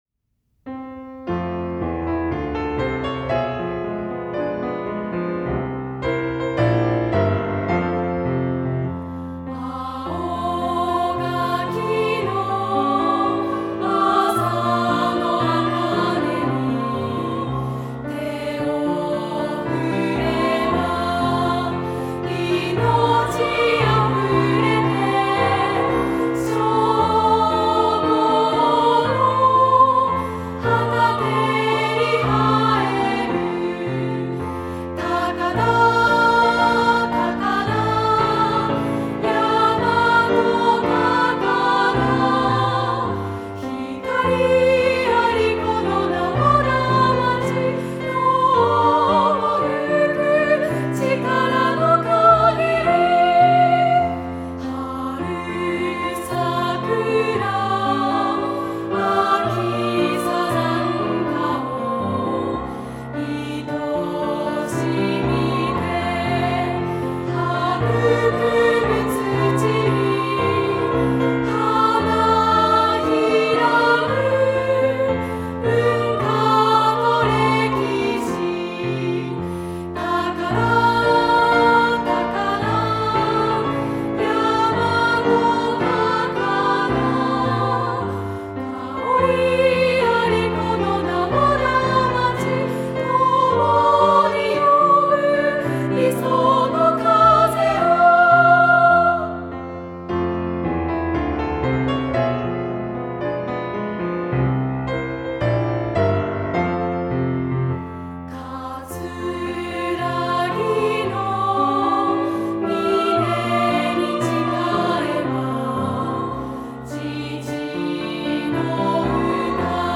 フルコーラス オブリガート入り